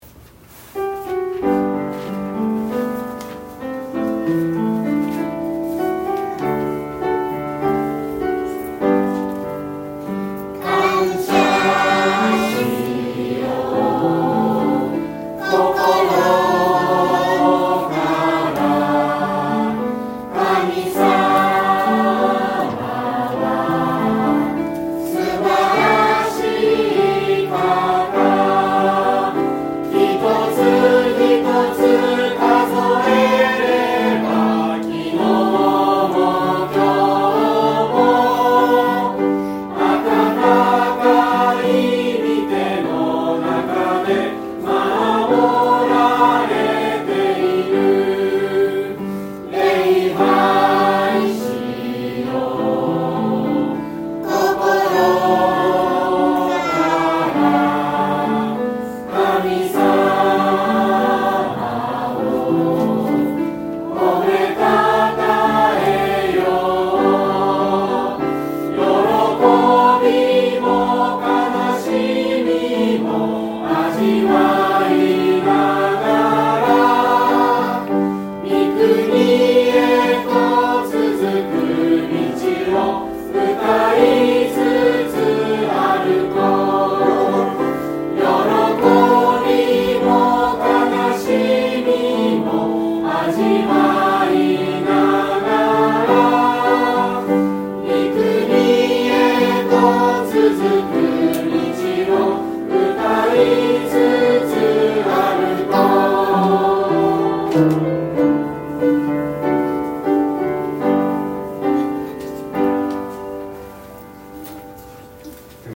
クリスチャン向けの賛美集。
音源は昨年の年末に教会で録音したものです。